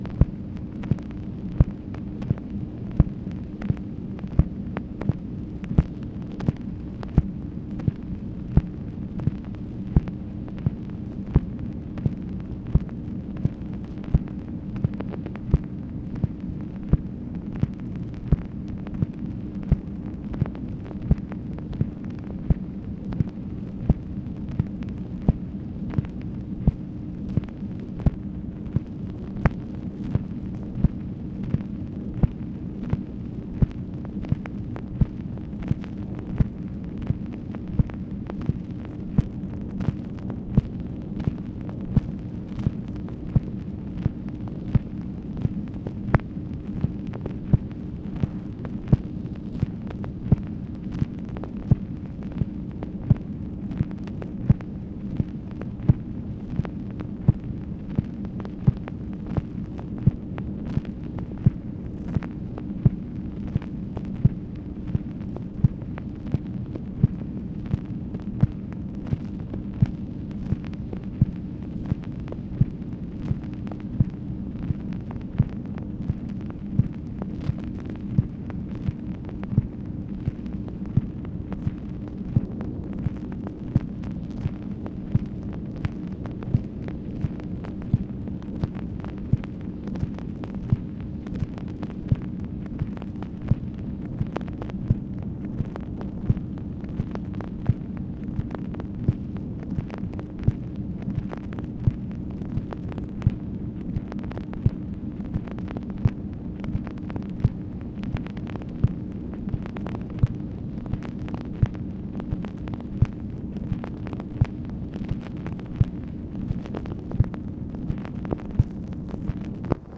MACHINE NOISE, September 19, 1966
Secret White House Tapes | Lyndon B. Johnson Presidency